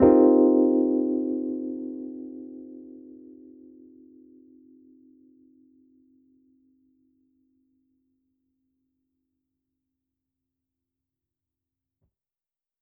JK_ElPiano3_Chord-Cm11.wav